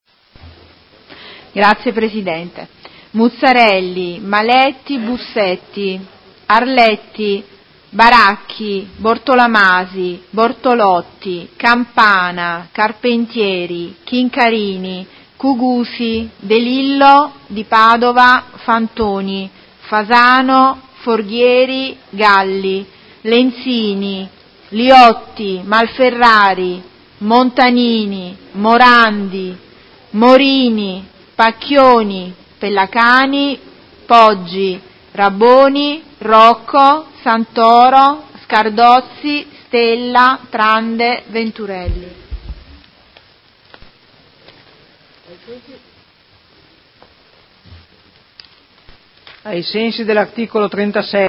eduta del 10/01/2019 Appello
Segretaria
Audio Consiglio Comunale